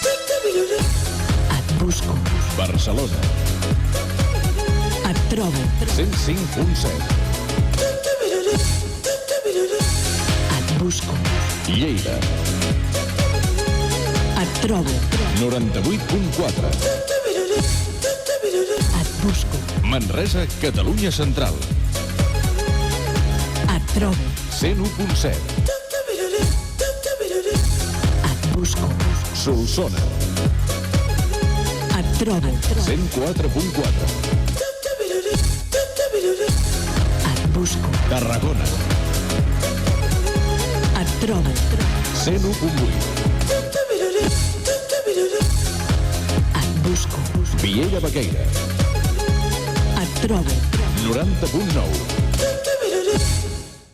Indicatiu de l'emissora i freqüències